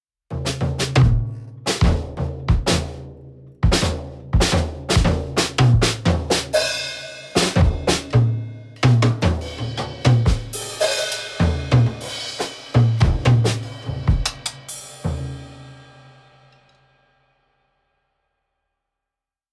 Percusión
Que barullo!
Como podedes escoitar os instrumentos de percusión son sen lugar a dúbidas os máis ruidosos!
Drumset_Falling_Down_Stairs.mp3